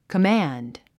command 発音 kəmǽnd